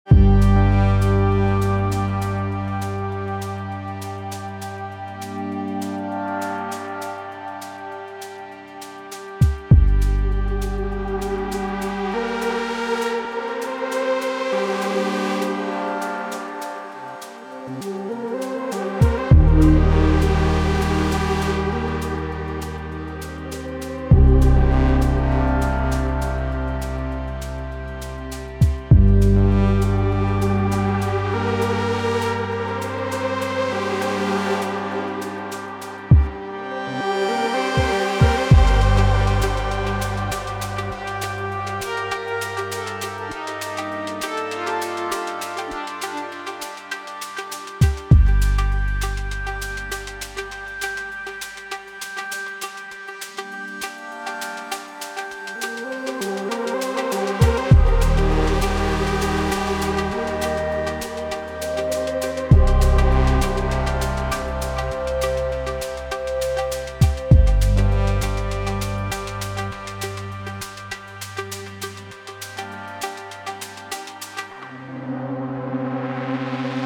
Here is a raw exerp of a track I am working right now. I´ve done it in 2 hours outside on the countryside with an external battery.